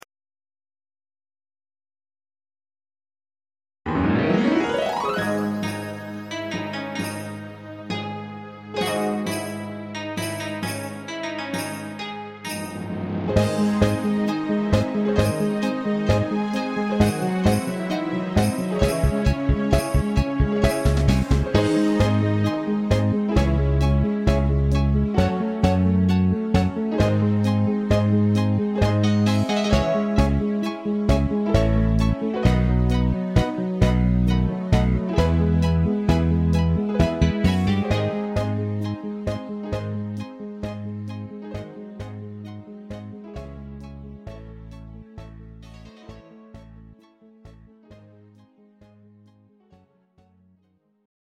Greek Zeimpekiko